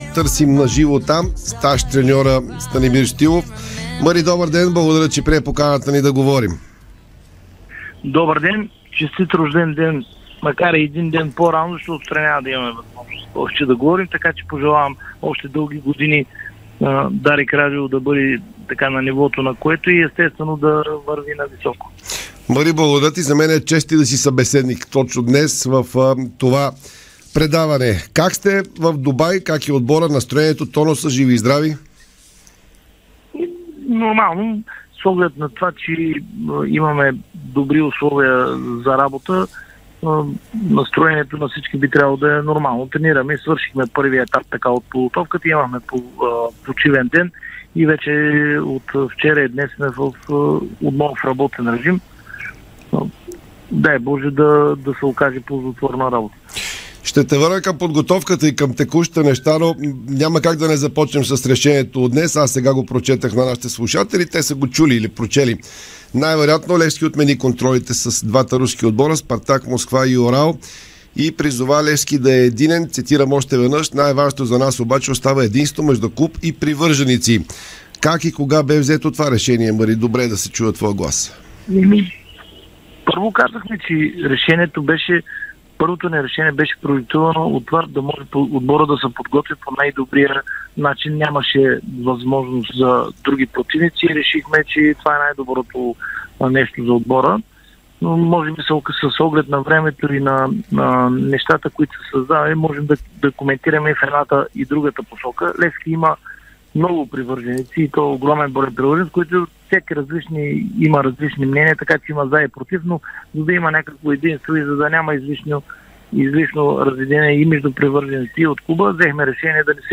Старши треньорът на Левски Станимир Стоилов говори ексклузивно на живо от Дубай в Спортното шоу на Дарик радио. Той говори за отменените контроли с руски тимове, селекцията, подновяването на първенството и потенциалната сделка за придобиване на клуба.